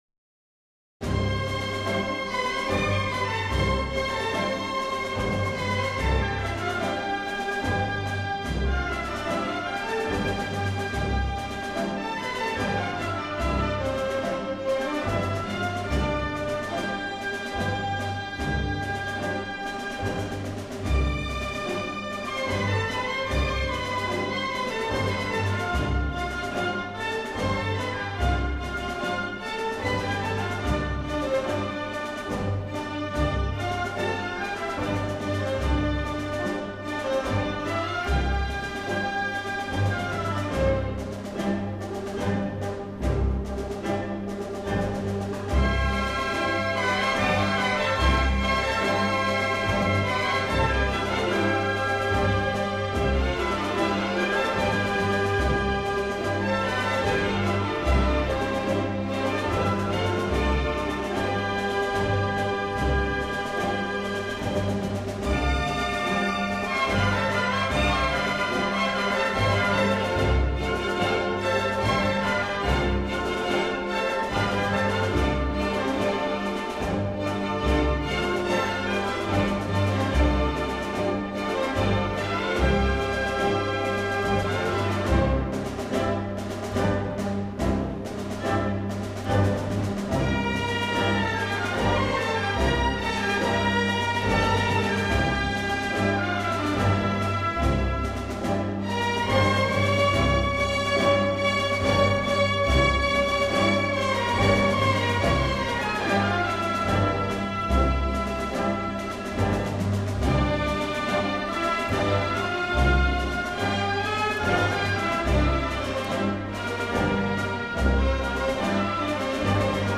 民间舞蹈风格的旋律是这部作品的基础。"